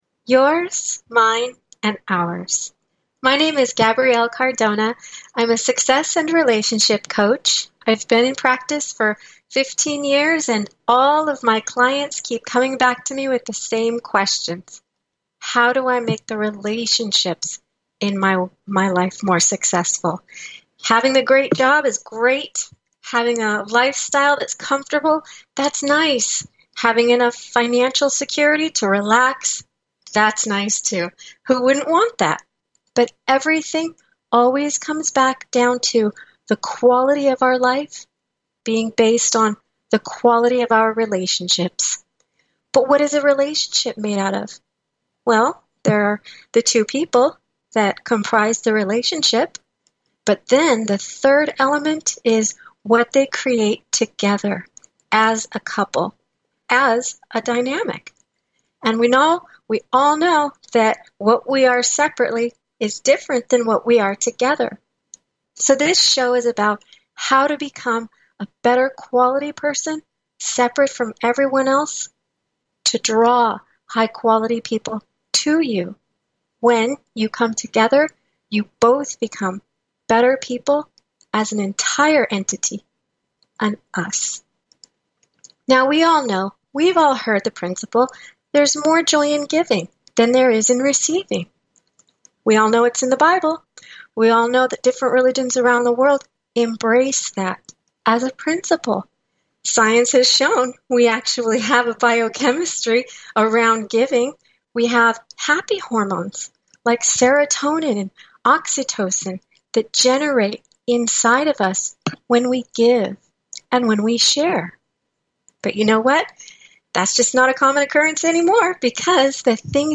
Talk Show Episode, Audio Podcast, Yours Mine and Ours and The gift of acceptance on , show guests , about The gift of acceptance, categorized as Health & Lifestyle,Kids & Family,Love & Relationships,Philosophy,Psychology,Self Help,Spiritual